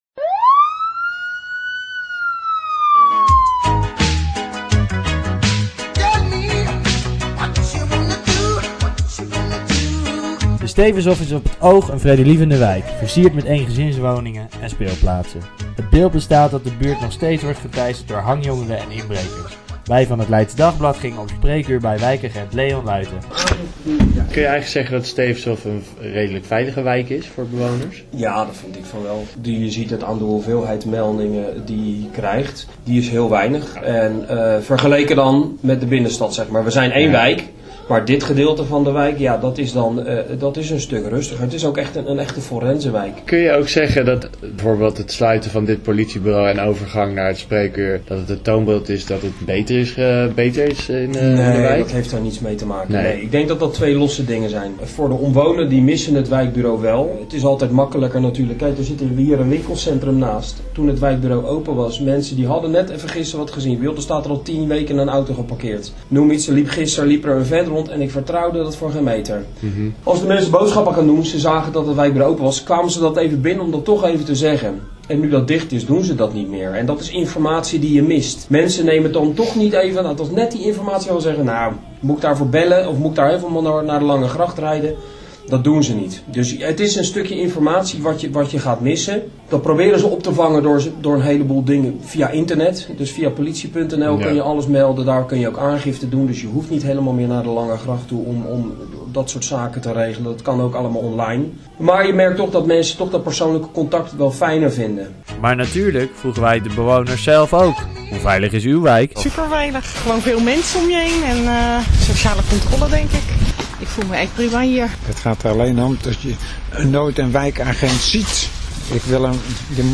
interview politie